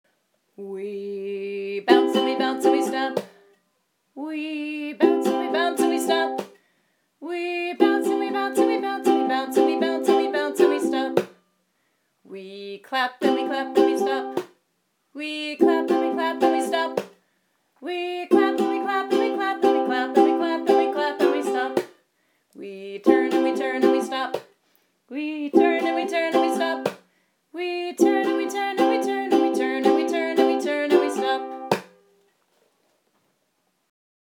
My New Favorite Storytime Song
The lyrics are really simple and adaptable, and it’s super easy to do with ukulele although if you’re going to bounce up and down while you do it, you will definitely want to invest in a ukulele strap (I have a Uku’Lei Sling that has been a lifesaver!). I slap the strings on the word stop to make it more dramatic.